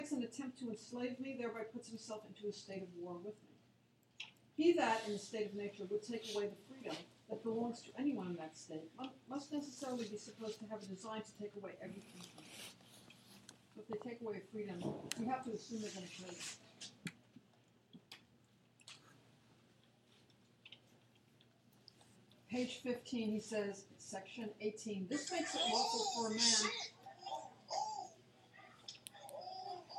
Field Recording 2
Someone’s cell phone goes off in class.